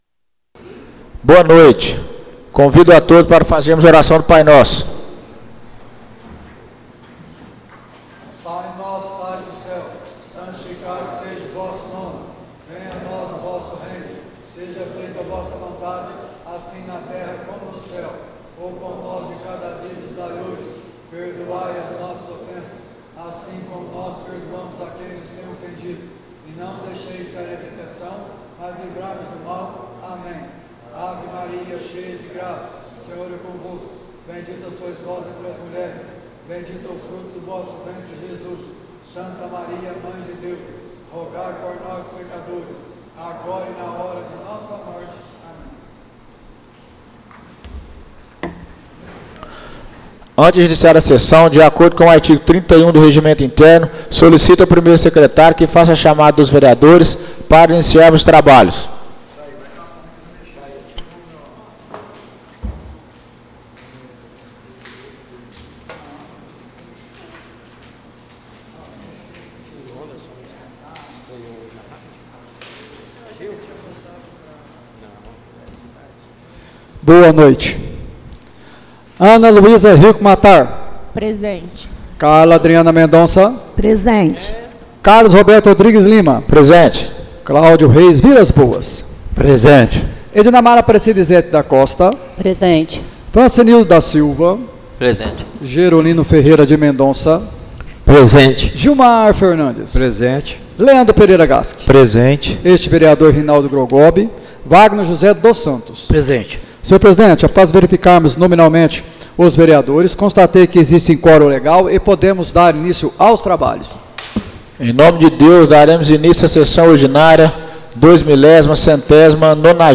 Áudio da Sessão Ordinária de 25/11/2024